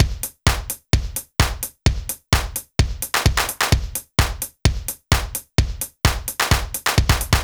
BAL Beat - Mix 3.wav